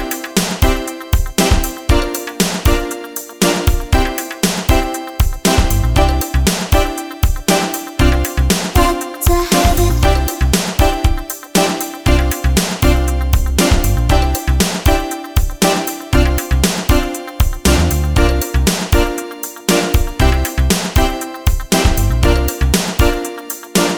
no Backing Vocals Duets 4:10 Buy £1.50